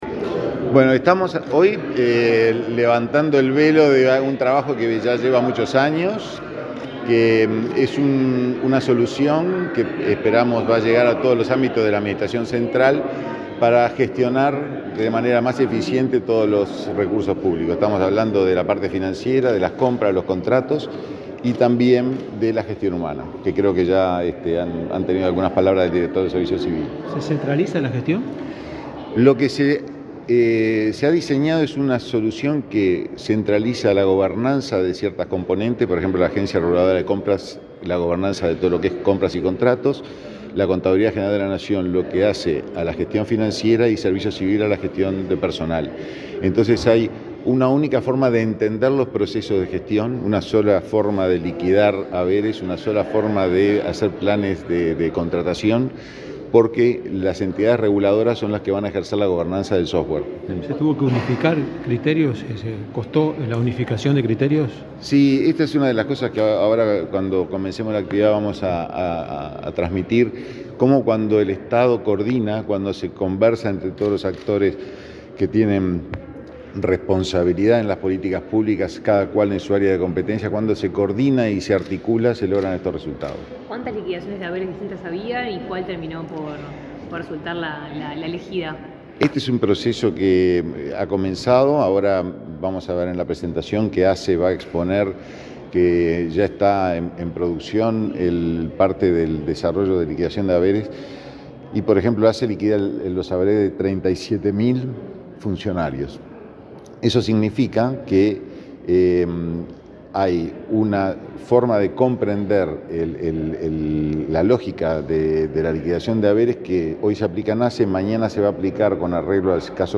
Declaraciones del director de la Agencia de Monitoreo y Evaluación de Políticas Públicas, Hugo Odizzio
Antes, dialogó con la prensa.